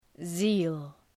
Προφορά
{zi:l}
zeal.mp3